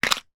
Звуки скрепок
Звук коробки со скребками на столе